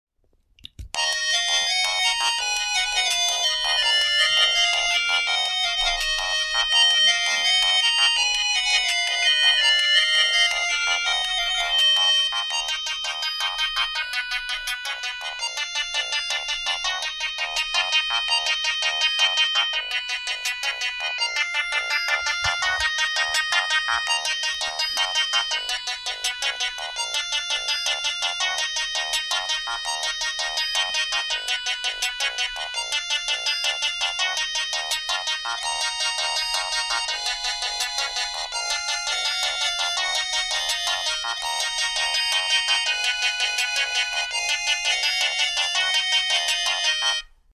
Siemens s5t ringtones sound effects free download